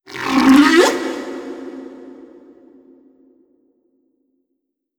khloCritter_Male13-Verb.wav